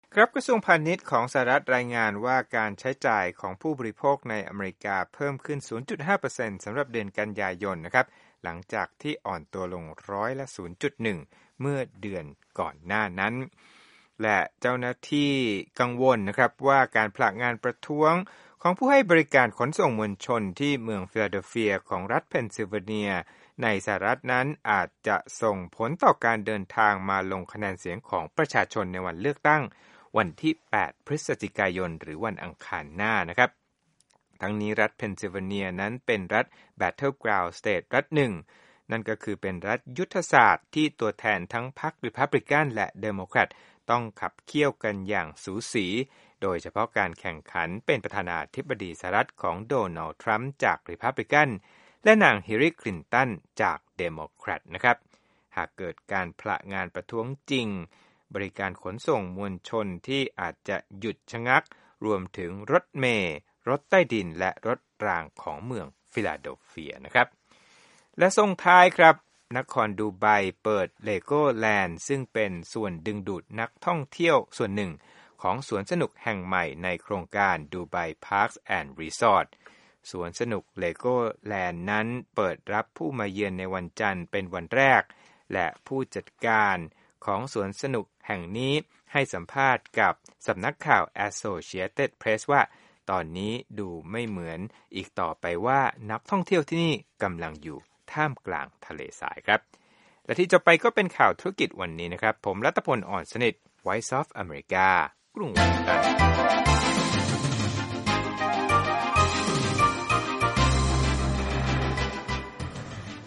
ธุรกิจ
Business News